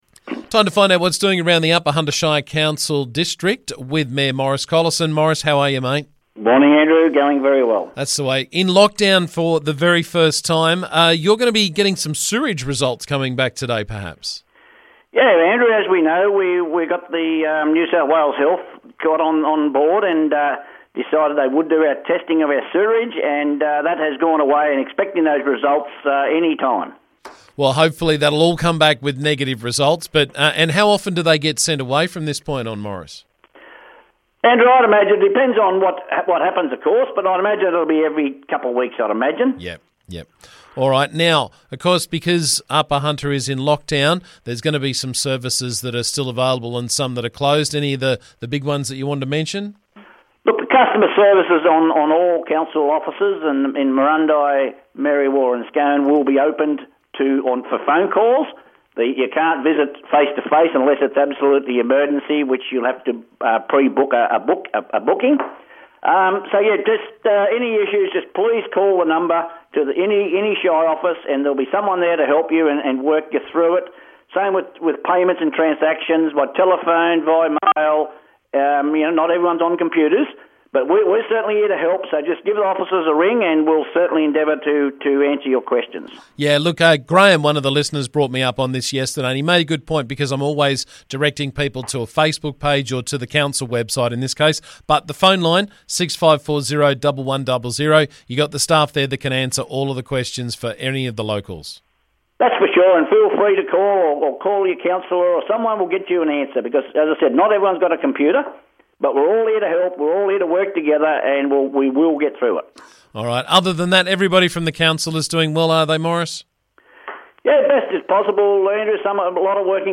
Upper Hunter Shire Council Mayor Maurice Collison caught us up with the latest from around the district.